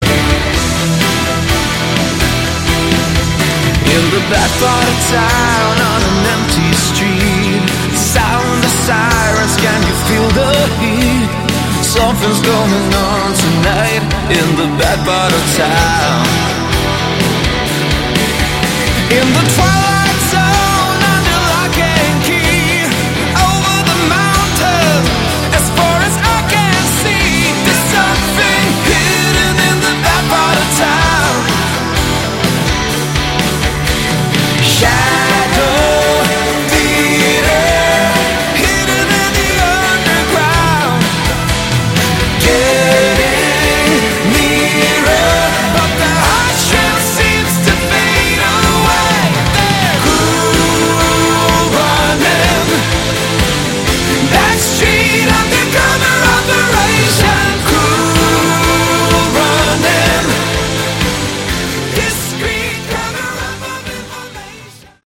Category: AOR / Melodic Rock
vocals, guitar
drums
bass